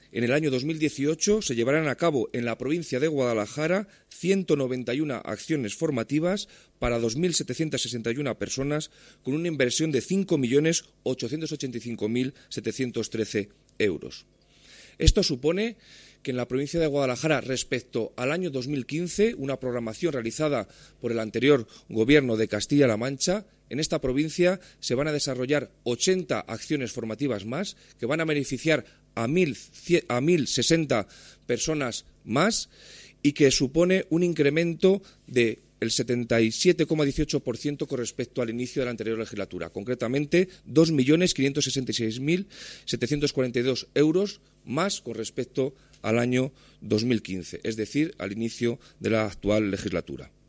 El delegado de la Junta en Guadalajara, Alberto Rojo, habla de las acciones de formación para el empleo que llevará a cabo en la provincia el Gobierno regional en el año 2018